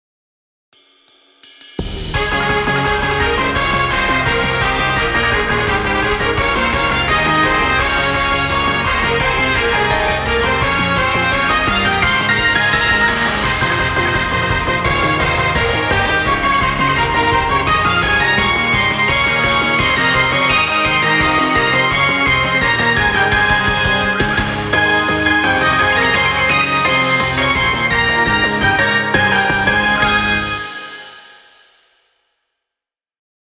どんちゃんわいわい、無法地帯。